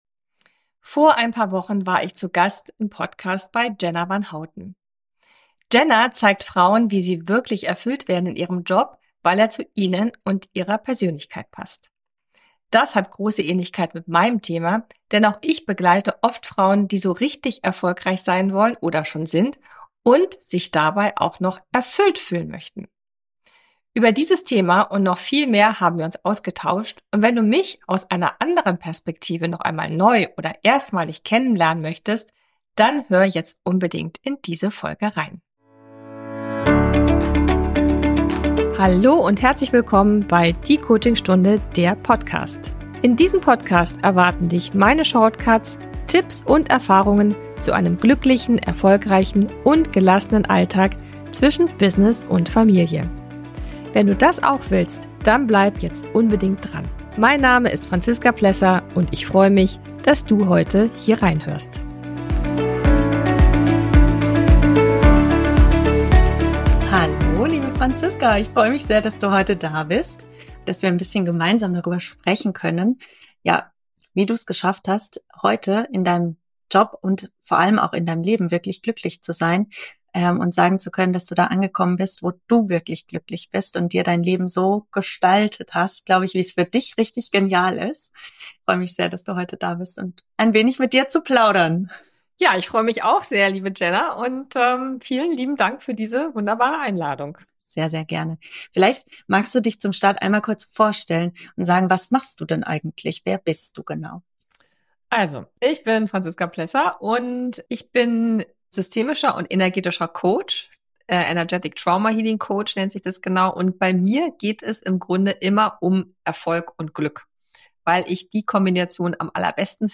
Über dieses Thema und noch viel mehr haben wir uns ausgetauscht und wenn du mich aus anderer Perspektive noch einmal neu oder erstmalig kennenlernen willst, dann hör sehr gerne in diese Folge rein.